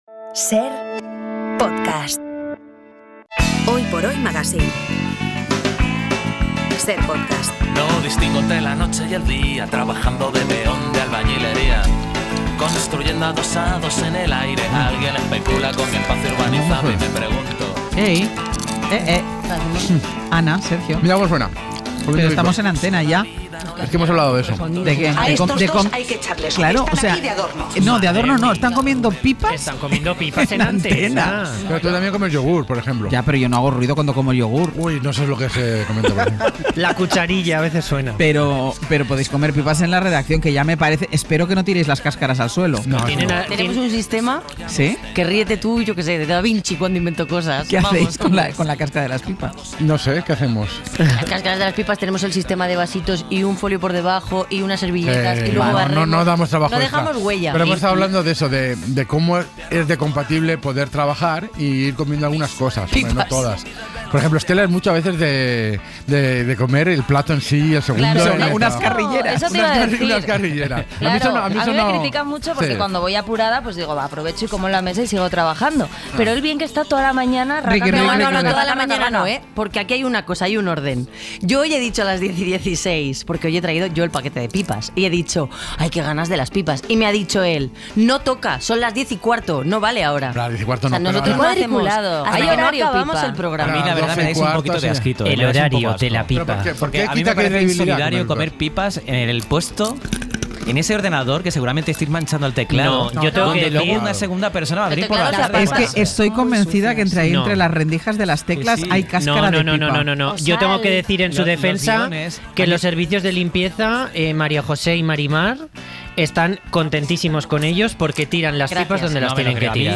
También lo han hecho hoy en antena. ¿Qué más comen en el Hoy por Hoy mientras trabajan?